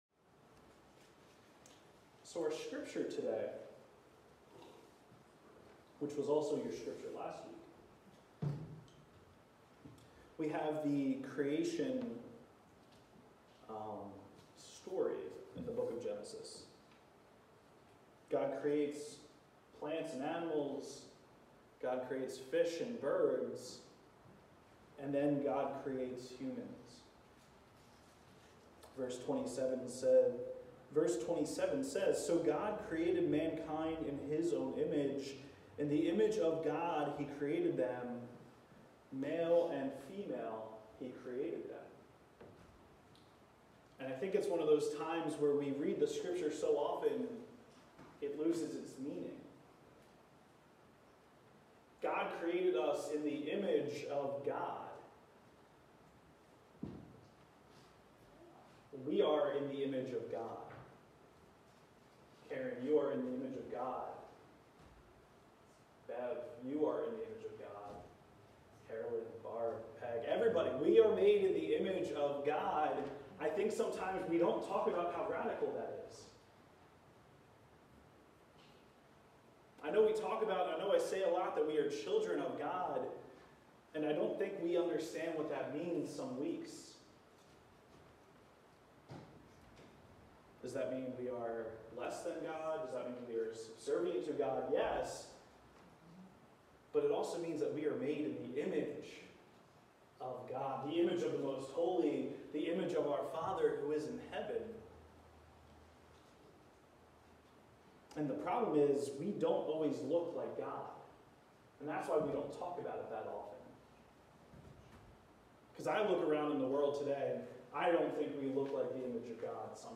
Series: Worship